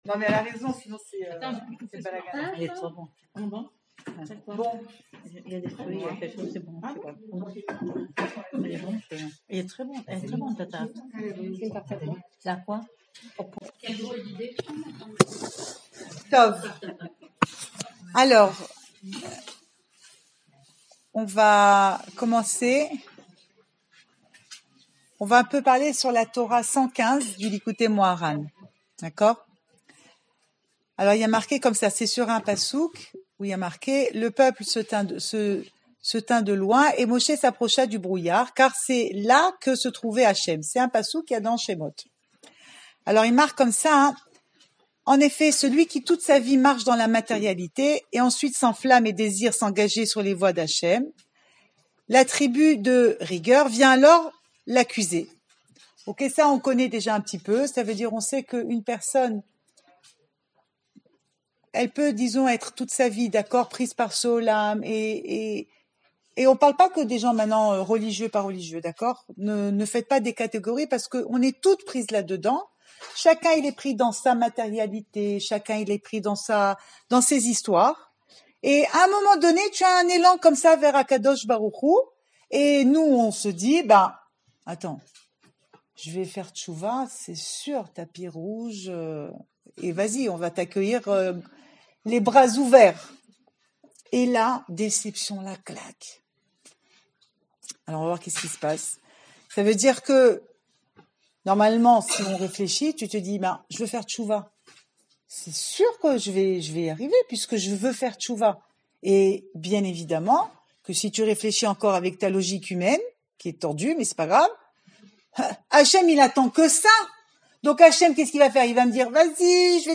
(enregistré en partie)
Cours audio Le coin des femmes Pensée Breslev - 4 décembre 2019 7 janvier 2020 Où se cache Hachem ?
Enregistré à Jerusalem